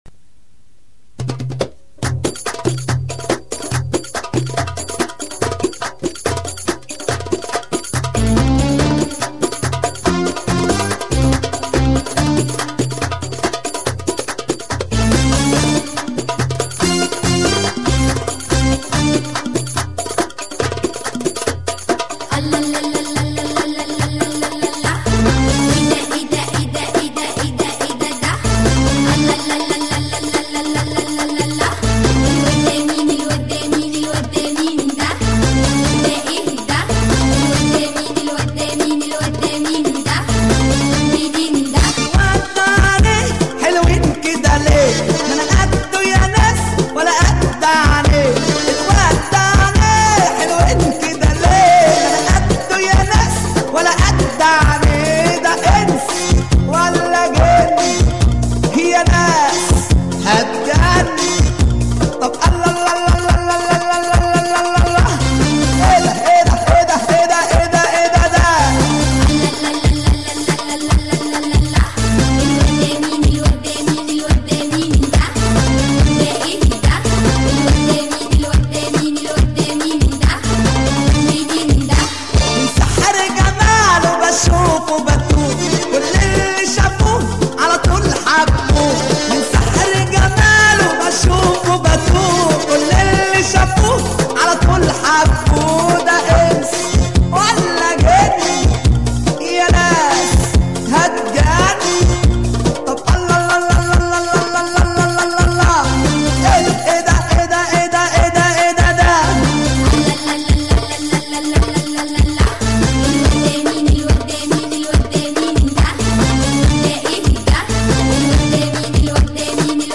Музыка со словами
Арабская